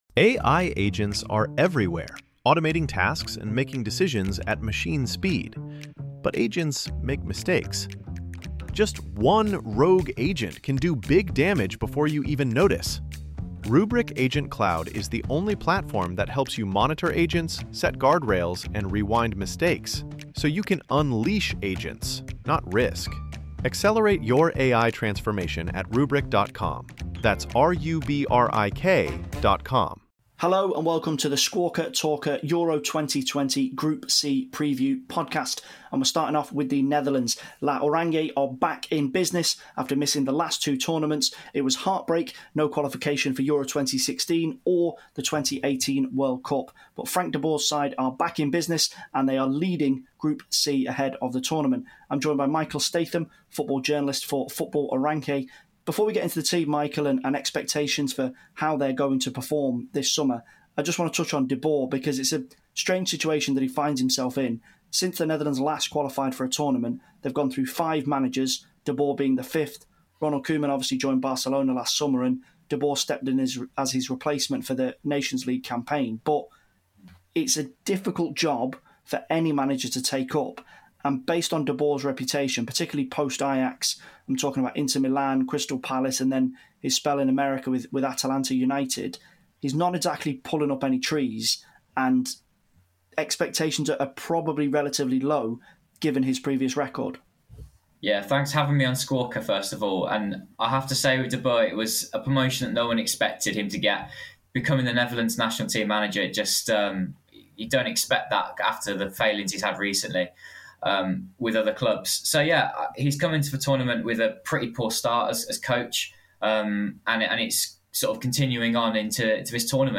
START - 00:15:34 | Netherlands 00:15:34 - 00:28:09 | Ukraine 00:28:09 - 00:43:37 | Austria 00:43:37 - END | Macedonia This episode was recorded in separate sections and all the stats and squads discussed were correct at the time of the final edit on May 16th 2021.